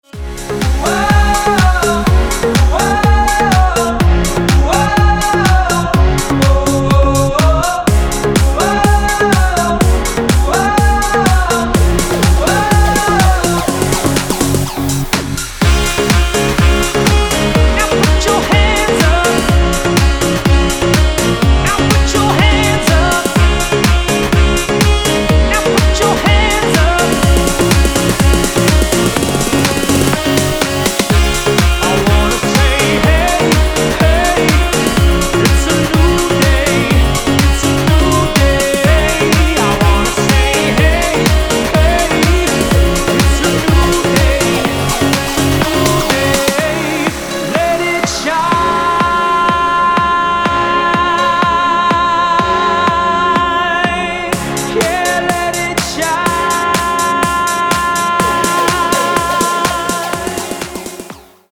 • Качество: 256, Stereo
позитивные
мужской вокал
громкие
dance
Electronic
EDM
электронная музыка
клавишные
Саксофон
Vocal House
Стиль: vocal house